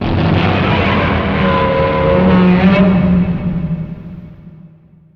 File:Godzilla '50s standard roar.ogg
Godzilla_'50s_standard_roar.ogg